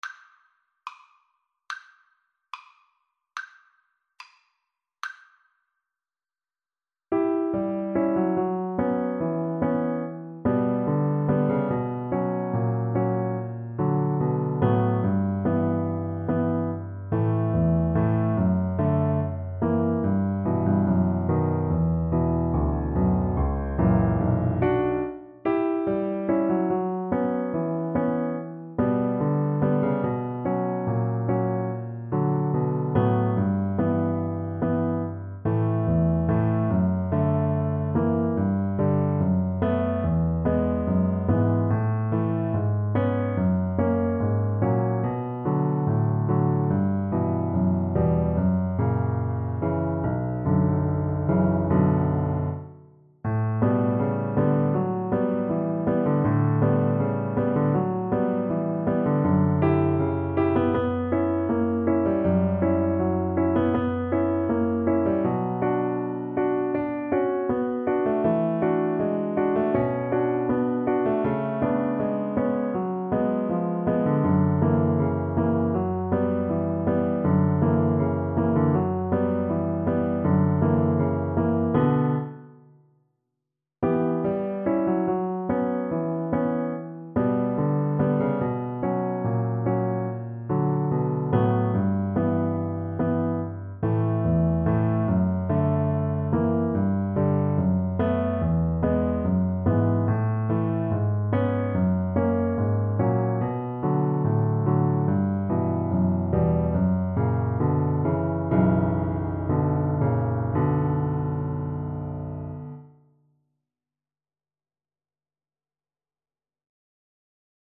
~ = 72 Andantino (View more music marked Andantino)
Classical (View more Classical Viola Music)